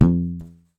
PVC_pipe_hit_4
block bong bonk bottle bumper container drop dropped sound effect free sound royalty free Music